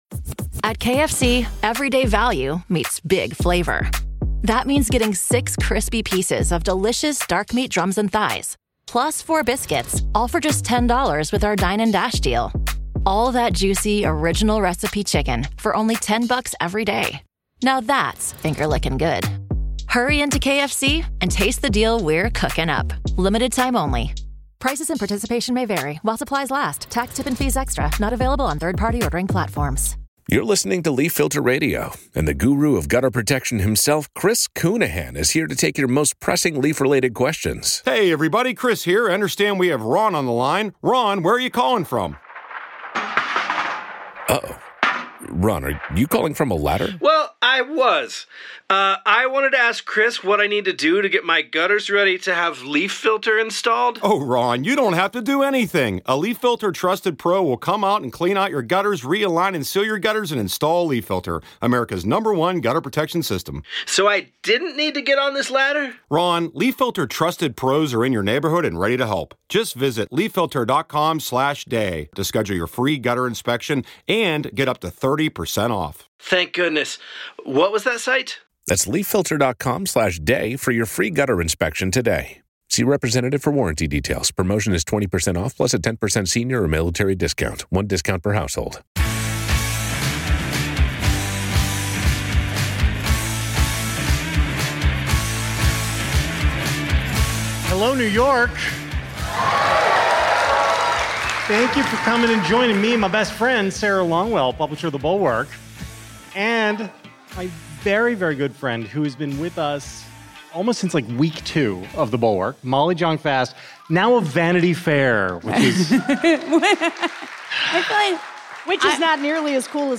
Anxiety vs Reality (LIVE from NYC with Molly Jong-Fast)